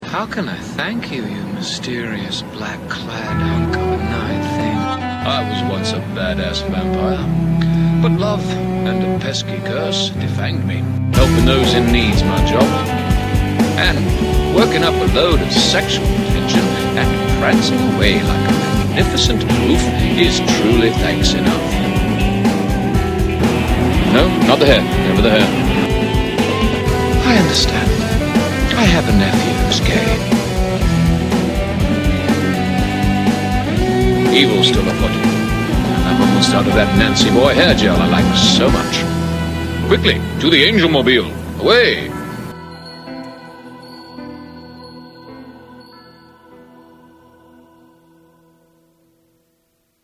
le génerique d'ouverture